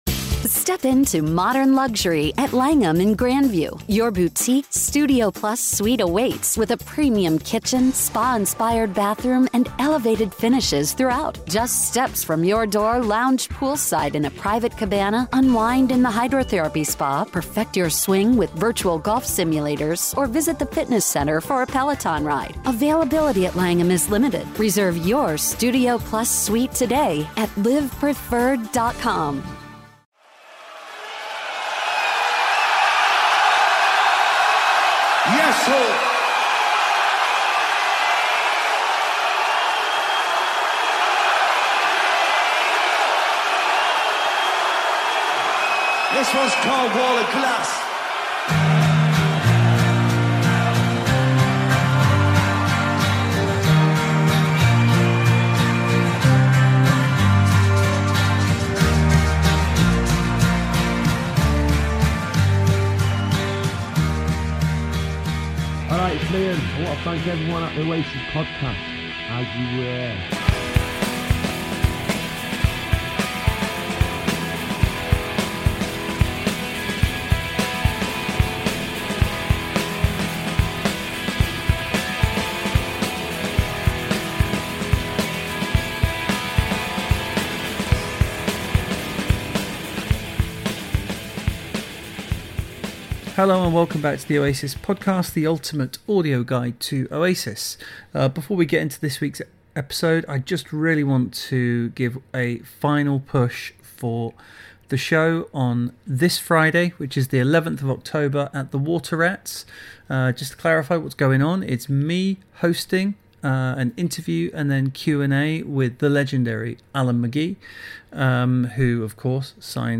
Exclusive Liam interview clips, MTV Unplugged Review, plus Noel gets spicy...